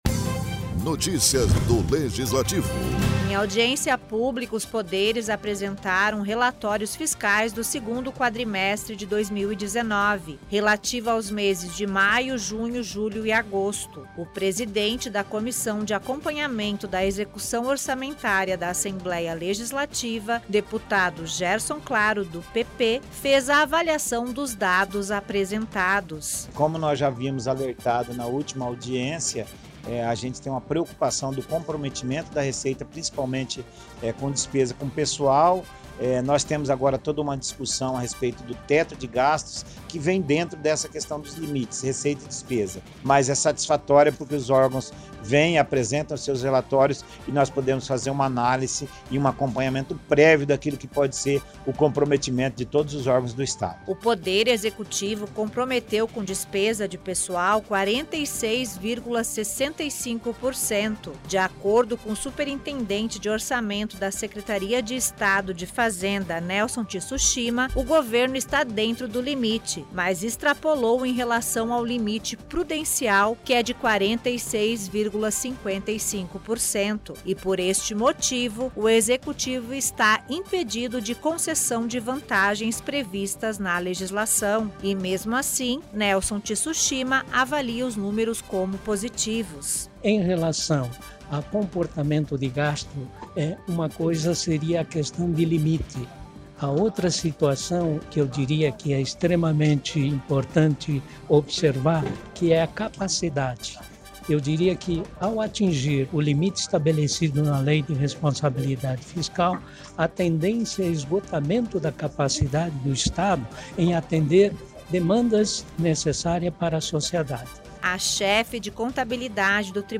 Os dados apresentados durante audiência pública de apresentação de relatórios fiscais demonstraram que os Poderes e órgãos autônomos do Estado se mantiveram dentro do limite de gasto com pessoal no segundo quadrimestre deste ano. As informações foram prestadas nesta terça-feira, na Comissão de Acompanhamento da Execução Orçamentária da Casa de Leis, presidida pelo deputado Gerson Claro, do PP.